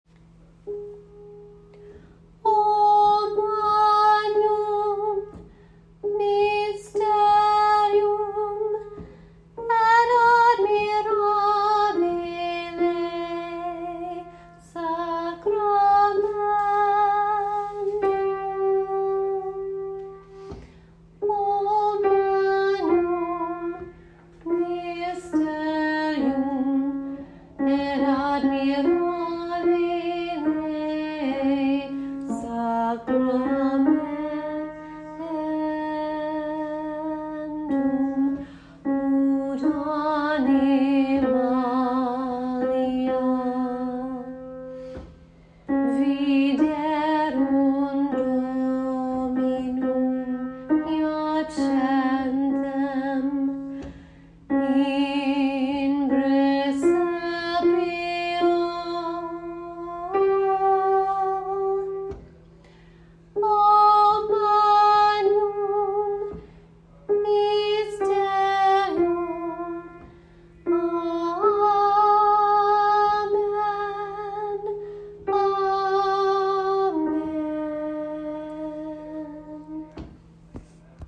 O Magnum Mysterium - Alto